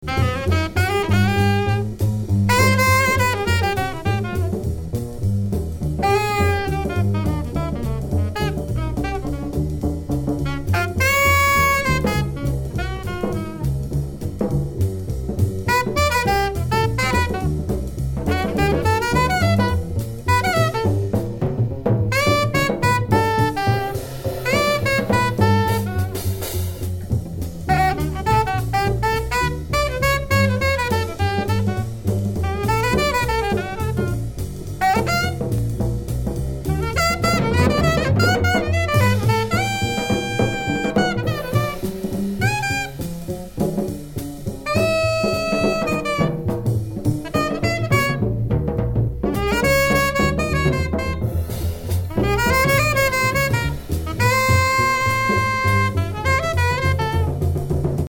The fifth album from the brilliant free-jazz saxophonist
pocket trumpet
bass
drums